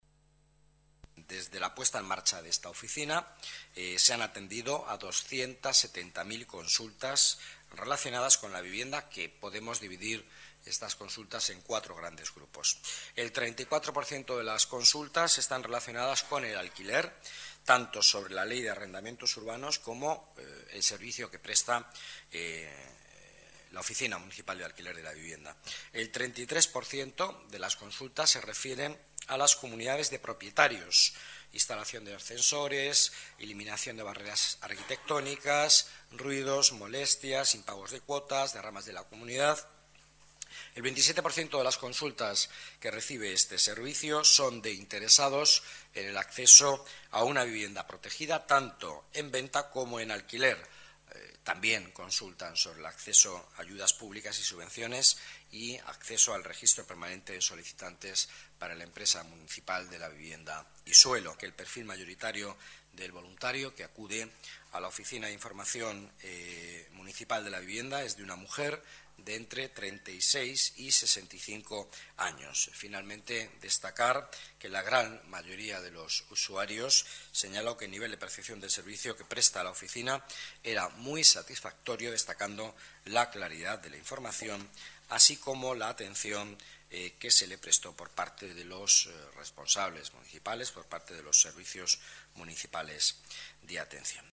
Nueva ventana:Declaraciones vicealcalde, Miguel Ángel Villanueva: datos vivienda